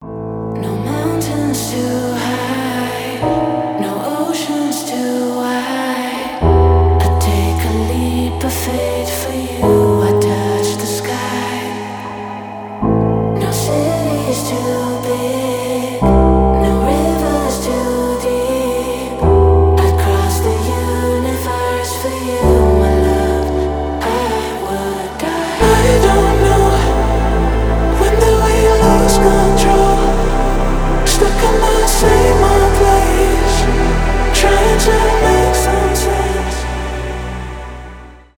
поп
красивый женский голос , спокойные , романтические